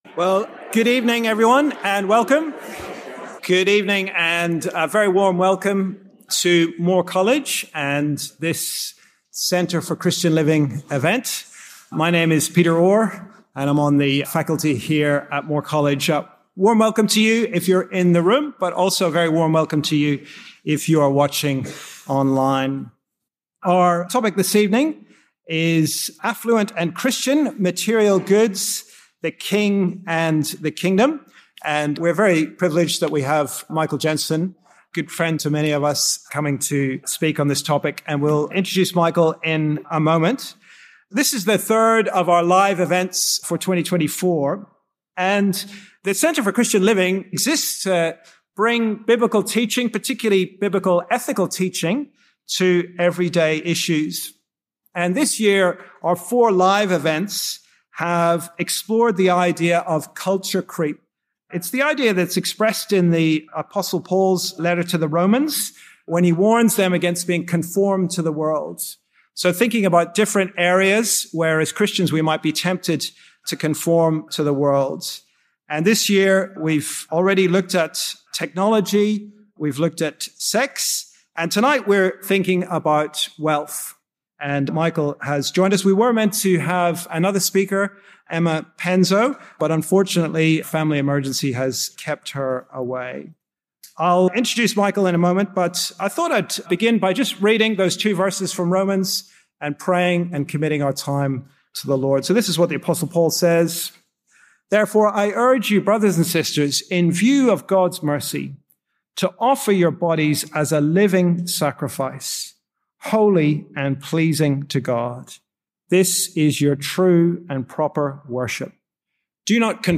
From our CCL event held on 21/08/2024 in Marcus Loane Hall at Moore Theological College.